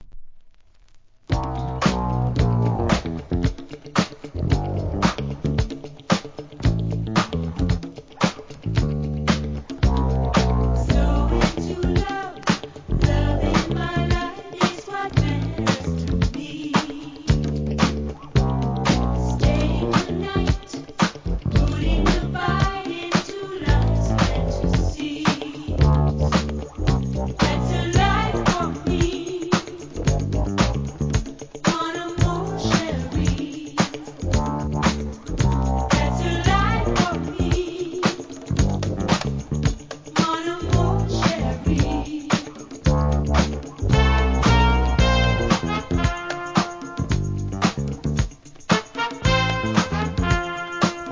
1. SOUL/FUNK/etc...
1983年の爽やかな人気モダン・ディスコ!!! UK